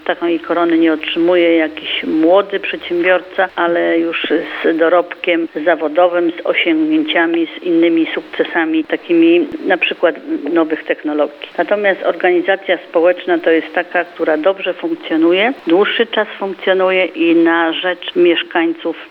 Jak mówi Bogusława Towalewska, burmistrz Wałcza, nie jest łatwo zostać wyróżnionym.
Burmistrz Wałcza.mp3